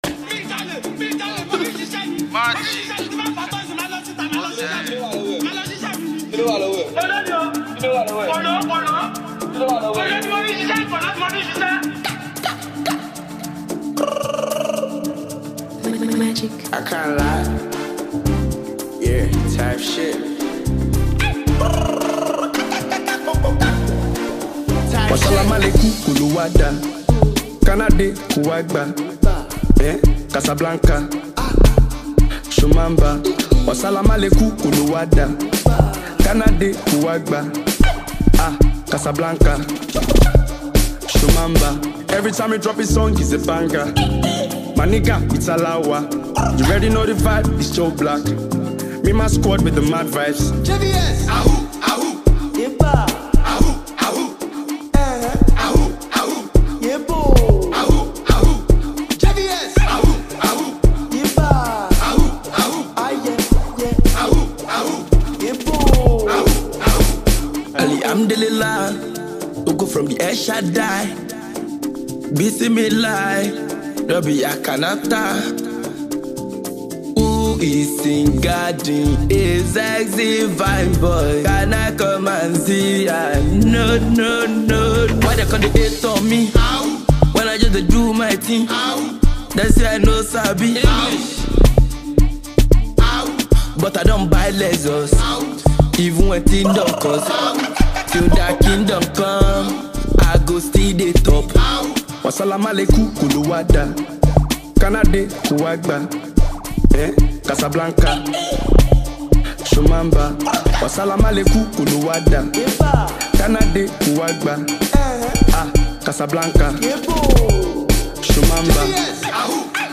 offering a unique, engaging sound.